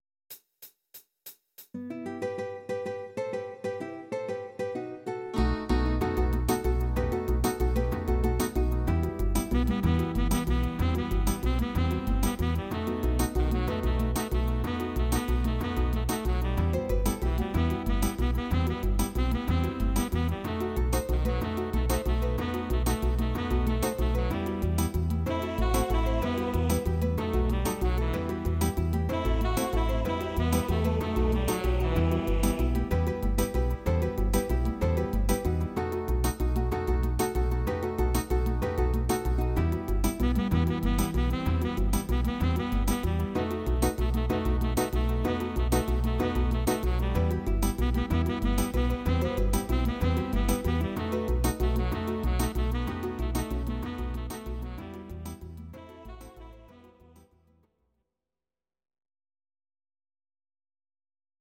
These are MP3 versions of our MIDI file catalogue.
Please note: no vocals and no karaoke included.
cover